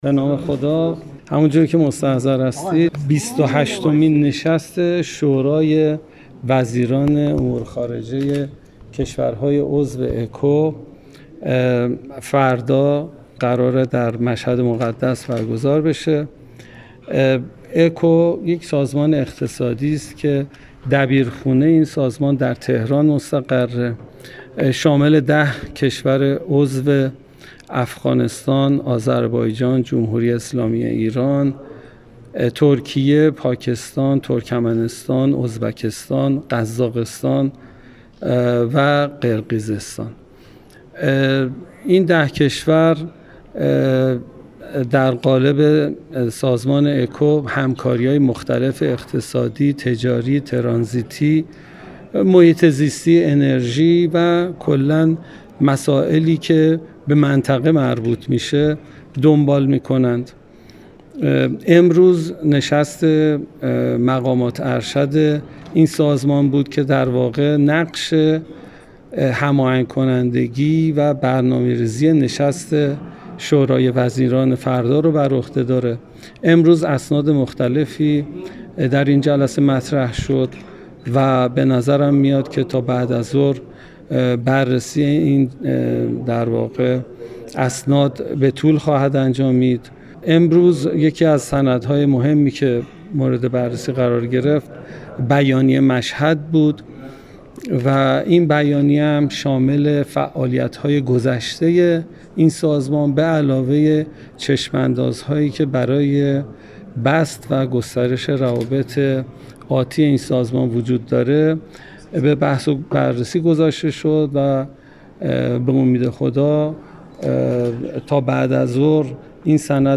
بهزاد آذرسا رئیس اداره اکو و سازمان‌های اقتصادی چندجانبه وزارت خارجه ایران در مصاحبه با رادیو دری گفت در نشست سازمان همکاری اقتصادی -اکو- در مشهد، نماینده ای از حکومت طالبان شرکت نمی کند.